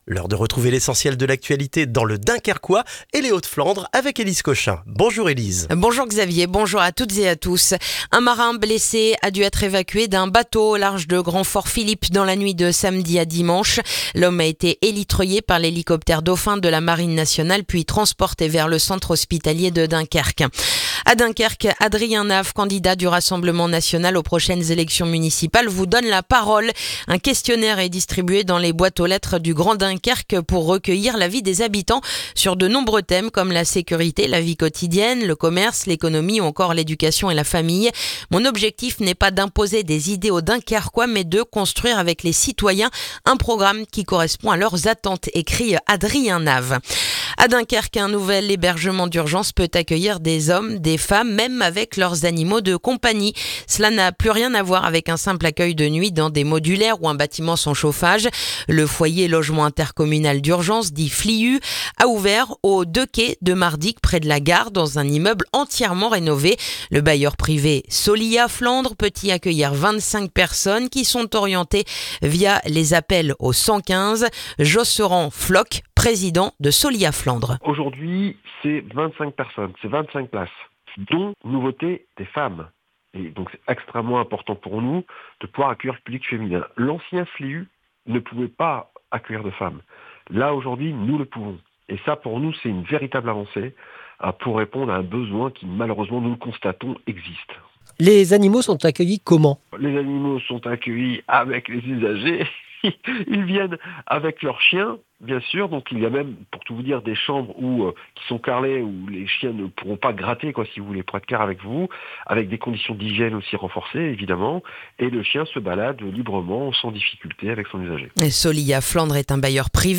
Le journal du lundi 8 décembre dans le dunkerquois